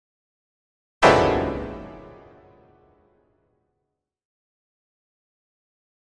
Piano Harsh Jarring Chord 1